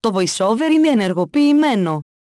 AXEFIAudio_el_VoiceOverOn.wav